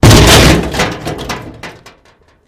Garbage Can | Sneak On The Lot